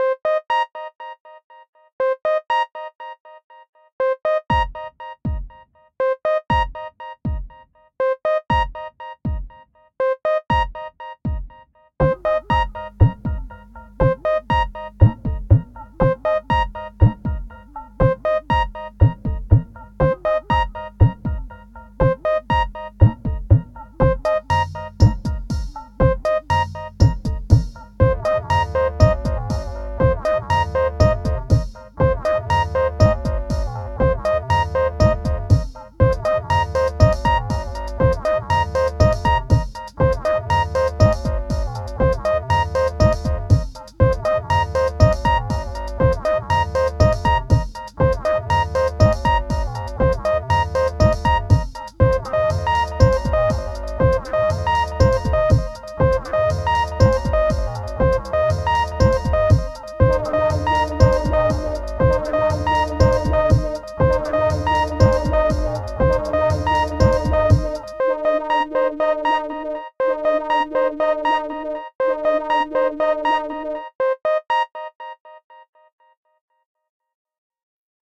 Pieza de electrónica minimalista
Música electrónica
melodía
minimalista
sintetizador